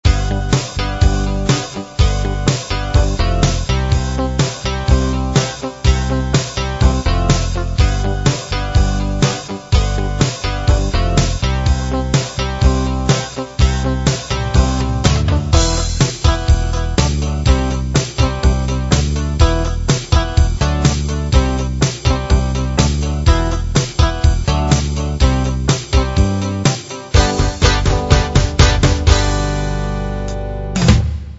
mp3 demo C&W-ROCK-POP-CCM